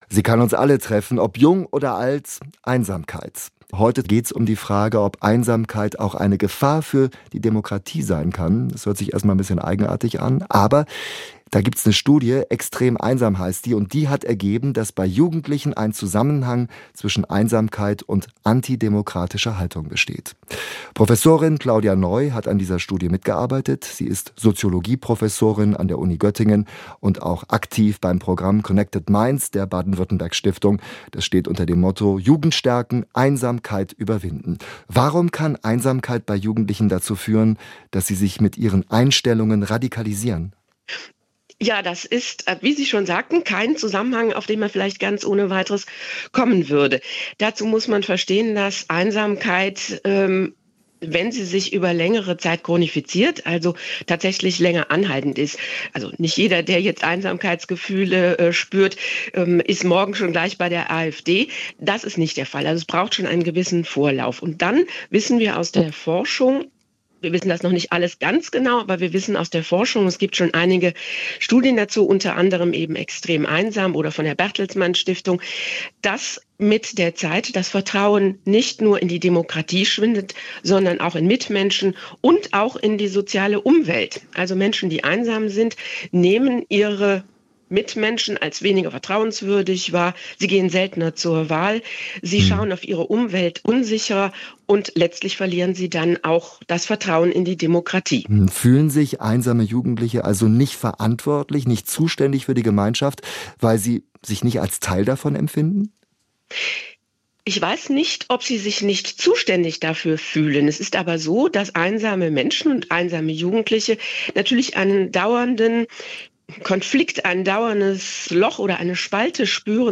Gefahren es dennoch gibt, erklärt sie im Interview.